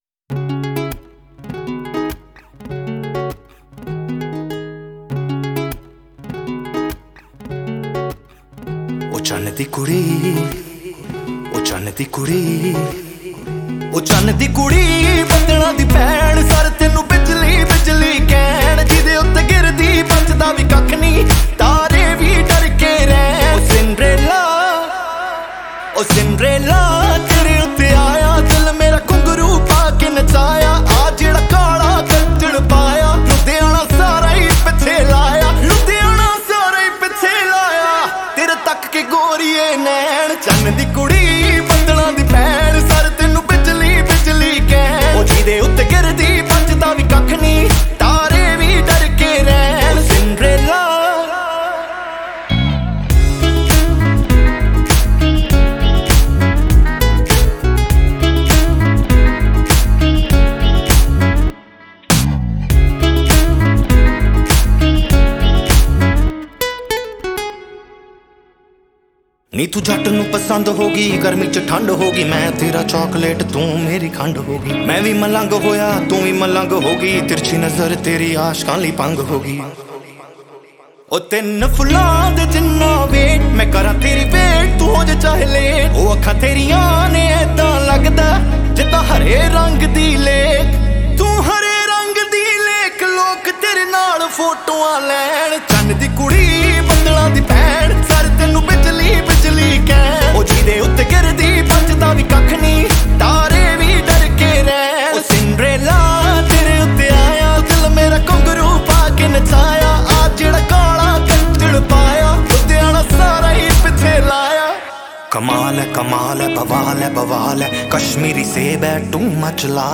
Punjabi Bhangra MP3 Songs
Indian Pop